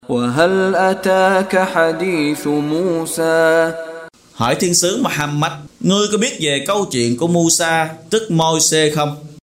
Đọc ý nghĩa nội dung chương Taha bằng tiếng Việt có đính kèm giọng xướng đọc Qur’an